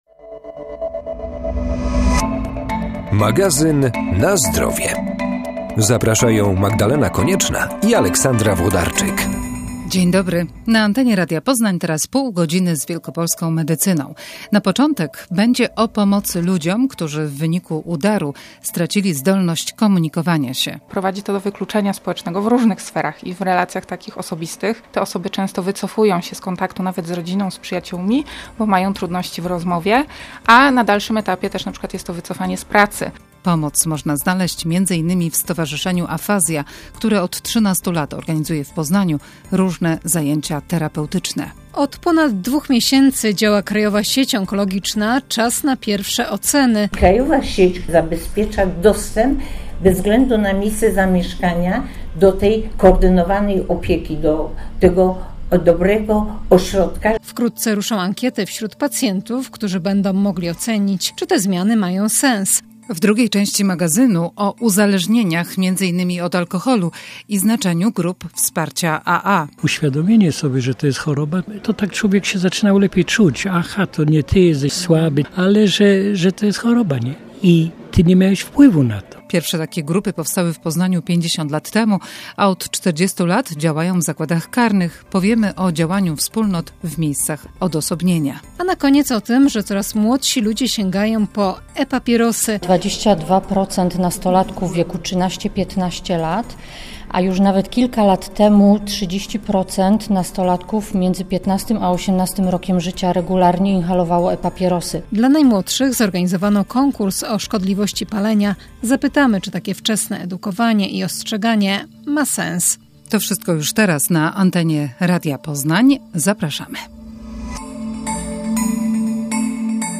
W magazynie rozmowa z założycielką o tym, na jaka pomoc można tam liczyć. Potem opinie pacjentów i lekarzy o działającej od ponad dwóch miesięcy Krajowej Sieci Onkologicznej.